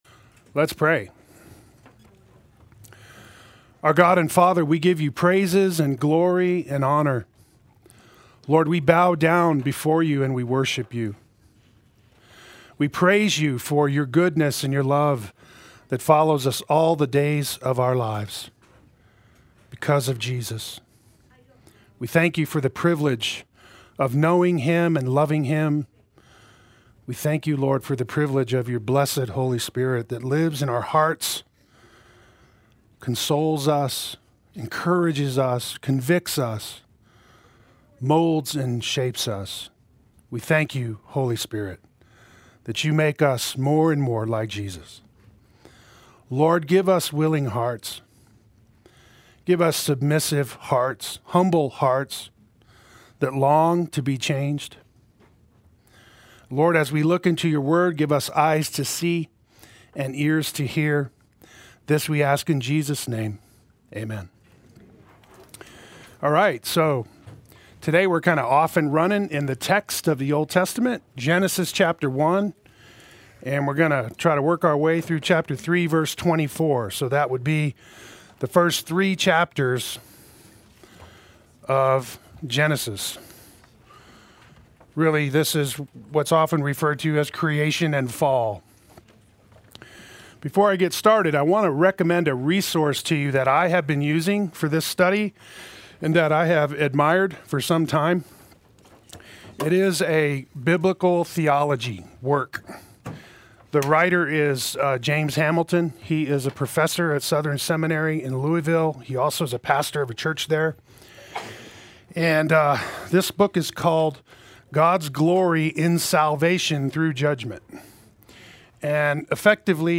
Play Sermon Get HCF Teaching Automatically.
Creation and Fall Adult Sunday School